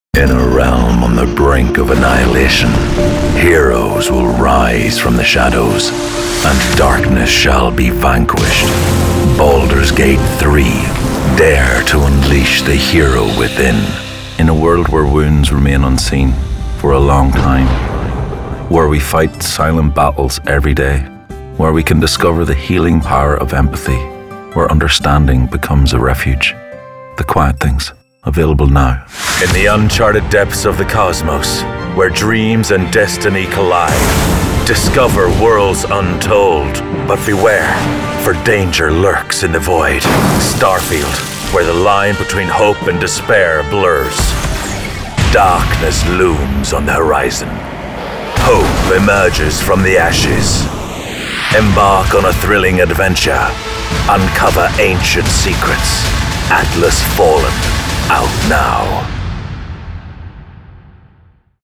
Trailers – games